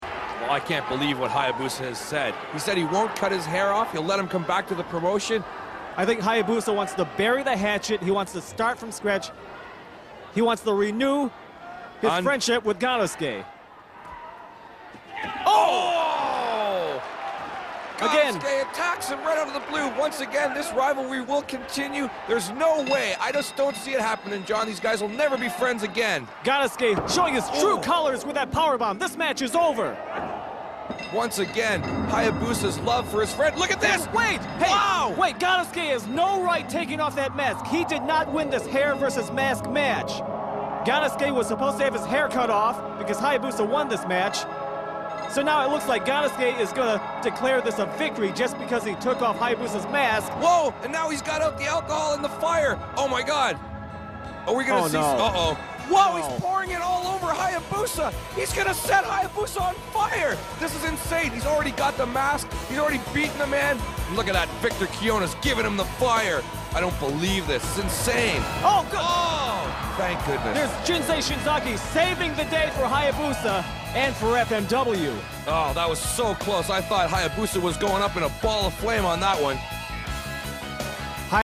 Just listen to the English announcers’
impassioned commentary.